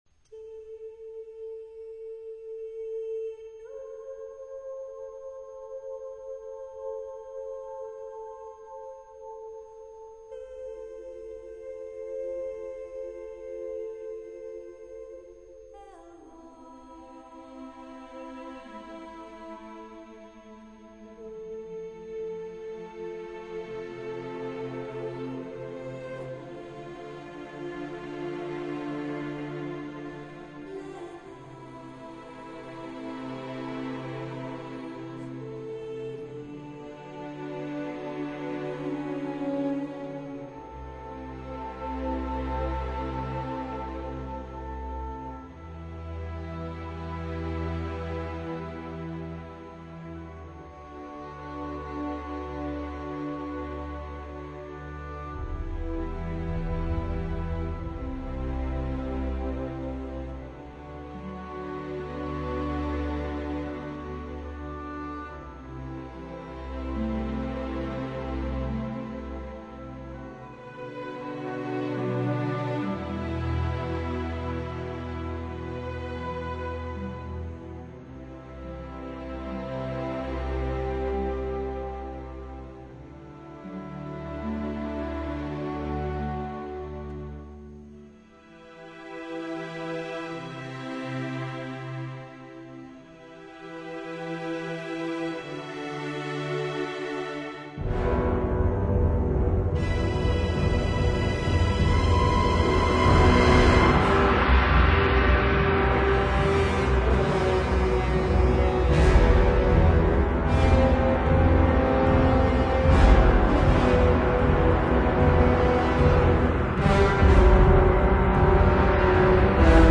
Exciting!